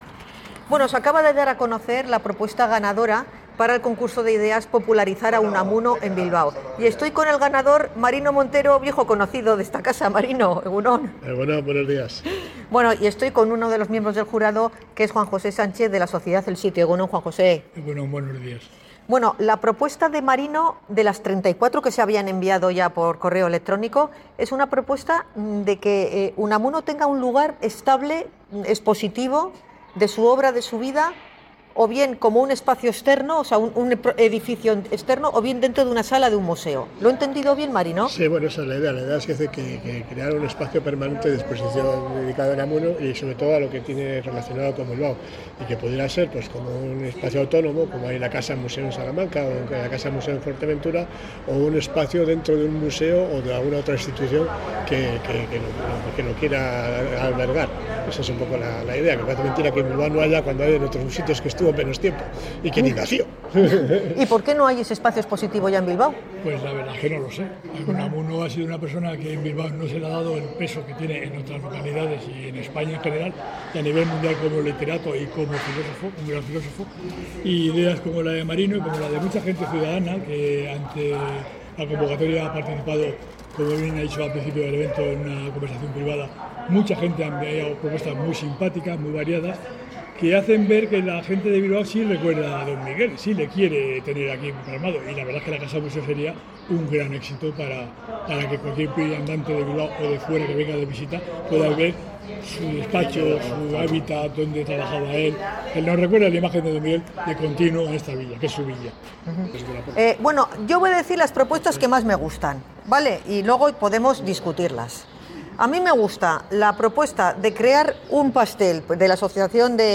Entre las sugerencias que se han comentado durante la entrevista también figuran dar el nombre de Miguel de Unamuno al aeropuerto de Bilbao, impulsar referencias más visibles en el transporte público o instalar una estatua de mayor presencia en la Plaza Unamuno, más allá del busto actual.